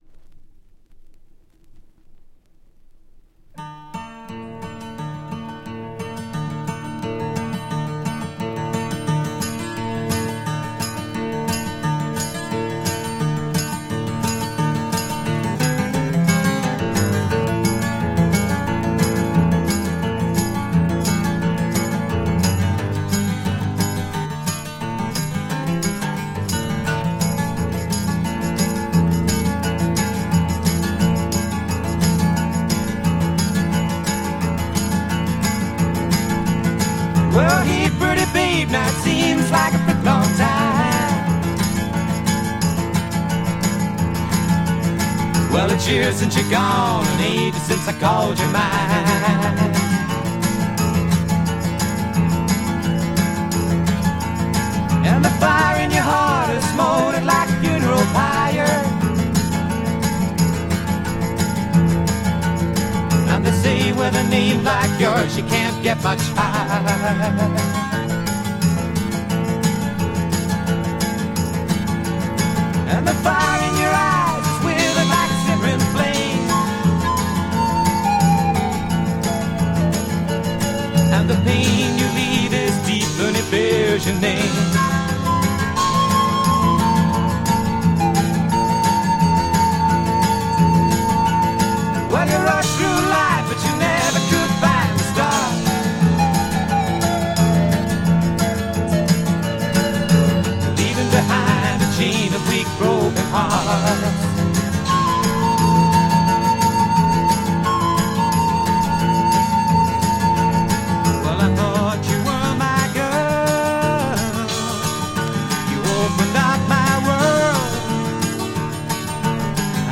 UK Folk PsychFunk French Album
the rest of the album is more folk wih little bluesy.